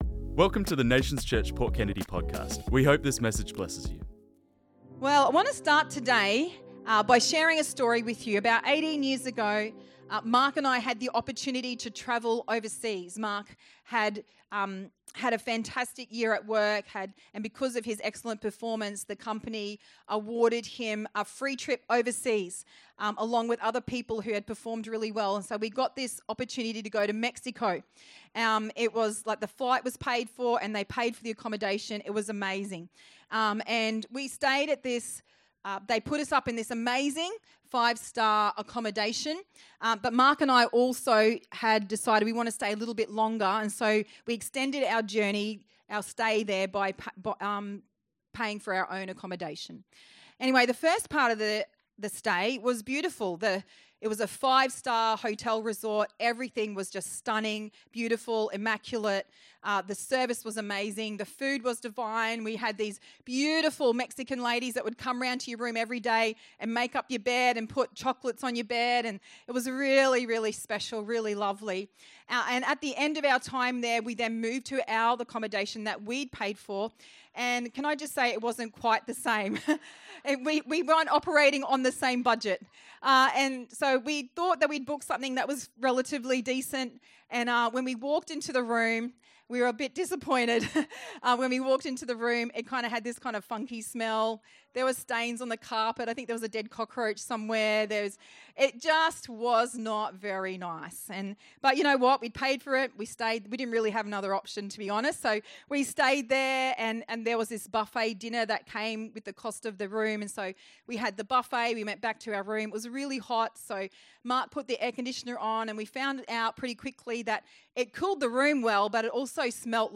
This message was preached on Sunday 3rd August 2025.